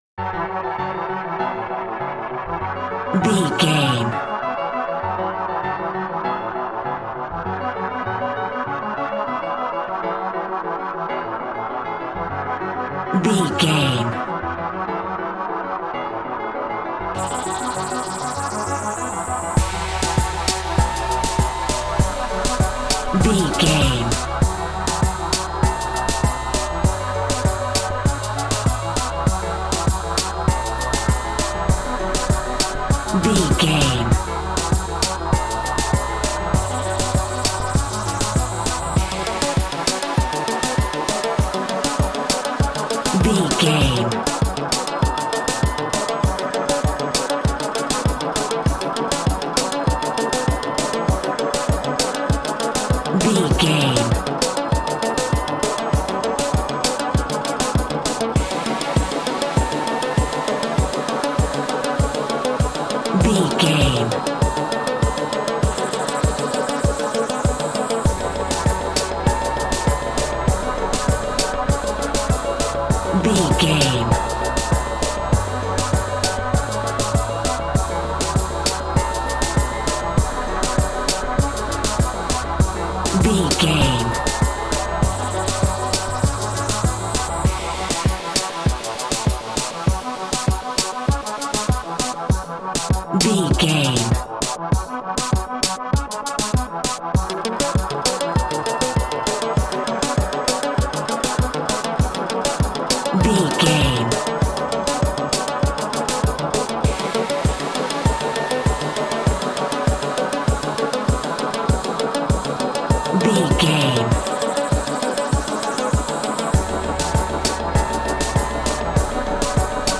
Classic reggae music with that skank bounce reggae feeling.
Aeolian/Minor
F#
laid back
chilled
off beat
drums
skank guitar
hammond organ
percussion
horns